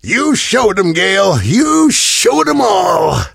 gale_lead_vo_01.ogg